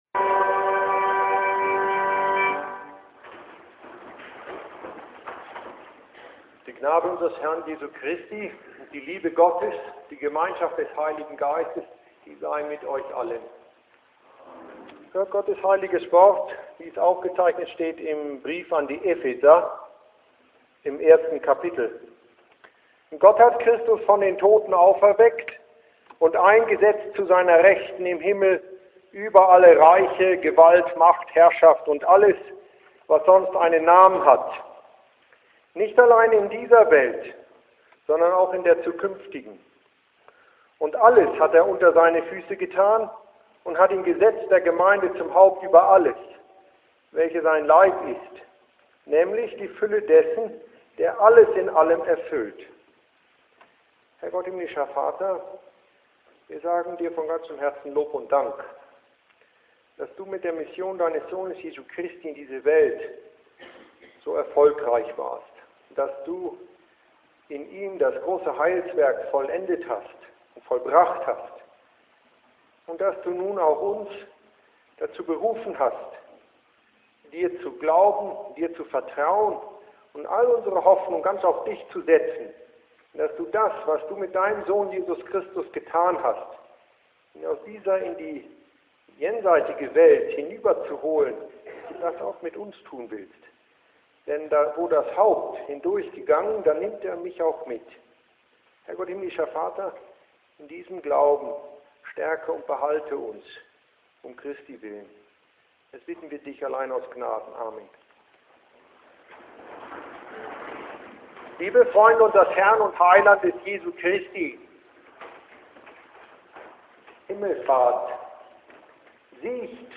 The German version is my sermon preached at St. Paul’s on Ascension Day.